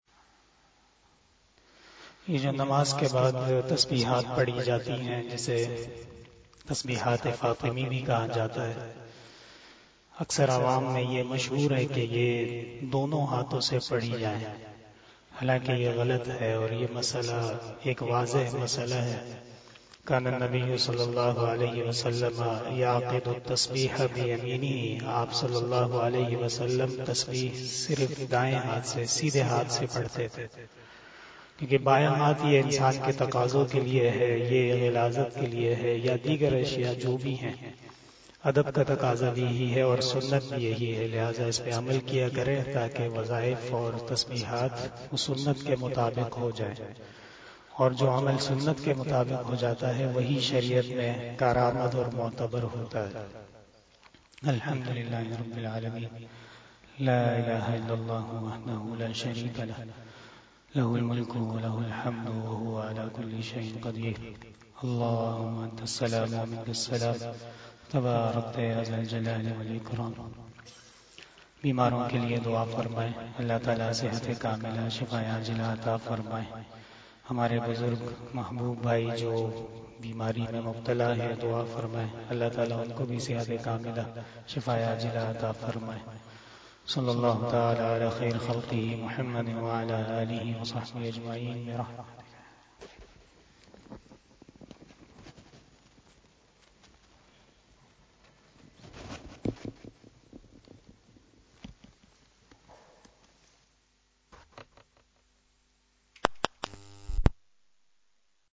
086 After Asar Namaz Bayan 23 December 2021 (18 Jamadal oula 1443HJ) Thursday